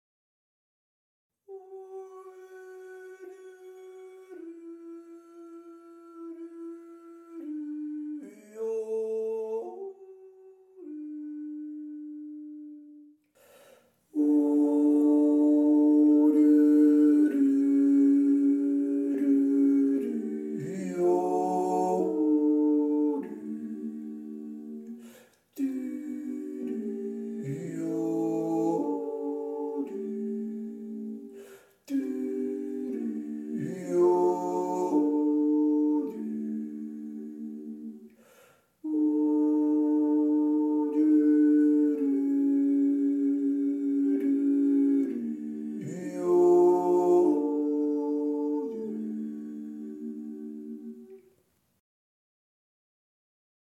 3. Stimme